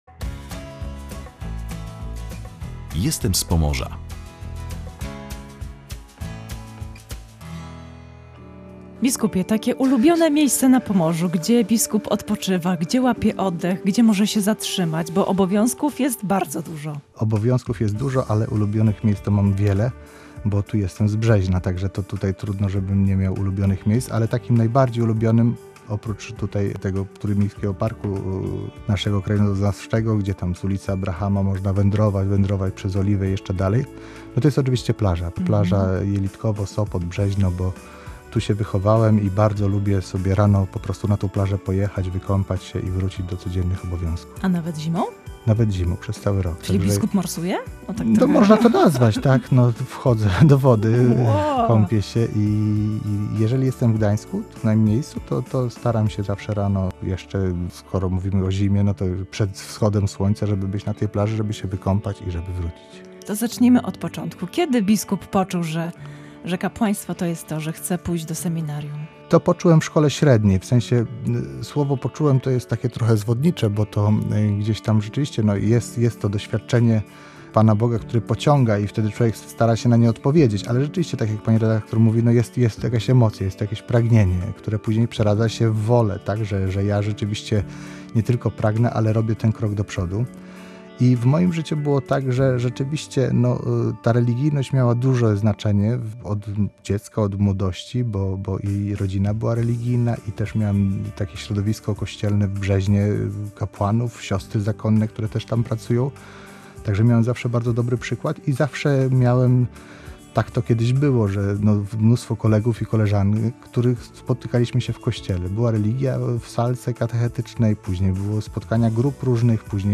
Biskup Piotr Przyborek w audycji „Jestem z Pomorza”: bądźmy dla siebie życzliwsi